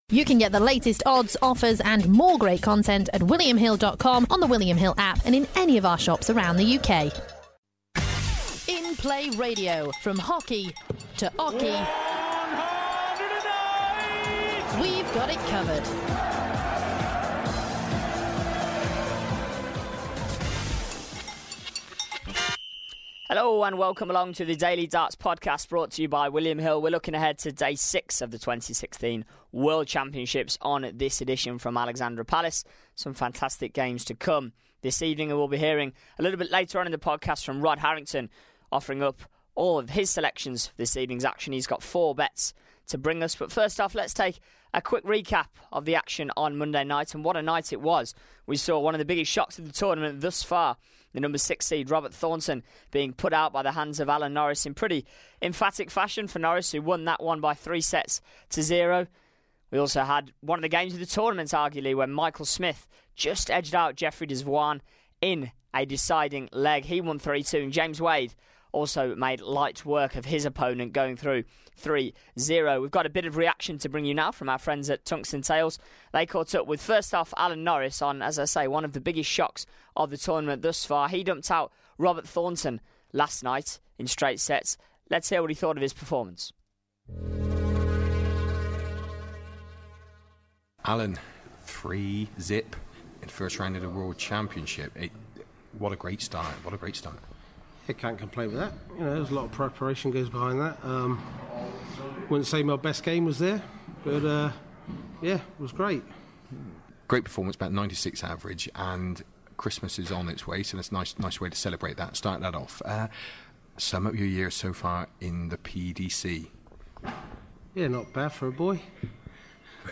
We hear reaction from the victorious players on this edition.